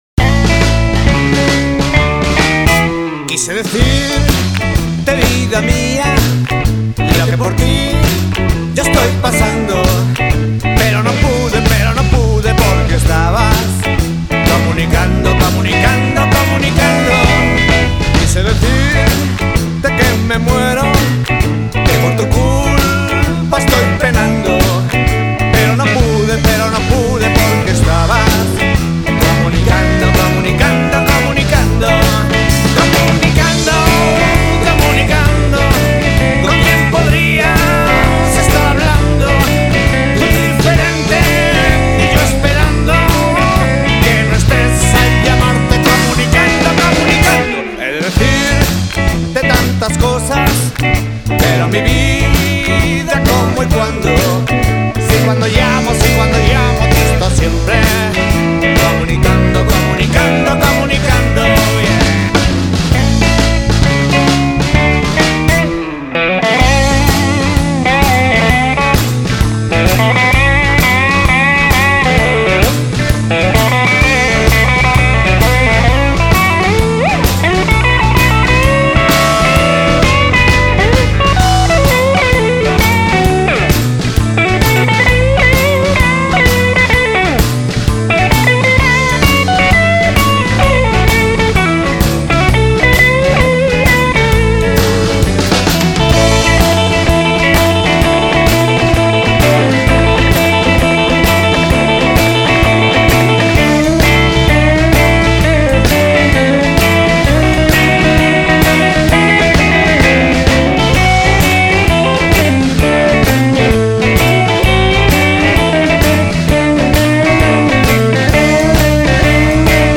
grabado totalmente en directo en un estudio móvil
sin más aderezos para su receta de Blues potente y directo
un disco sencillo, crudo y sin adulterar